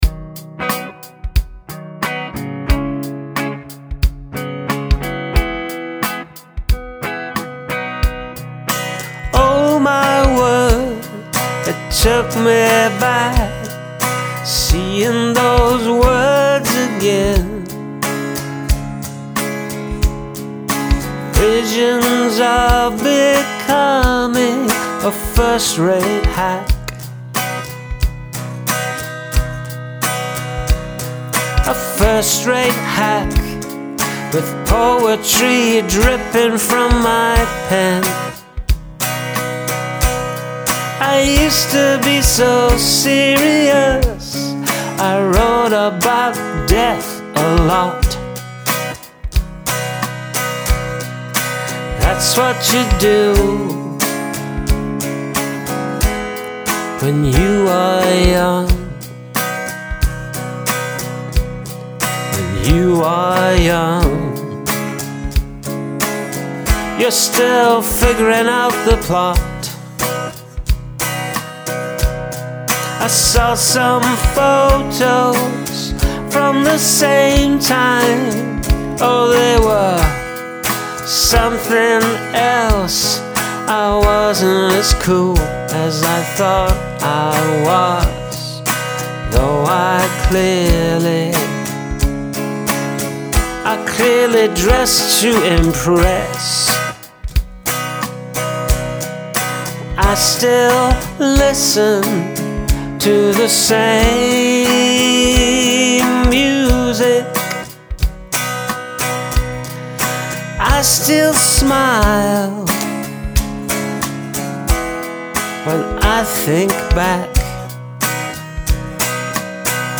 This is giving U2 vibes.
Love the guitar, melody, and honest lyrics that feel like a gentle self-reflection.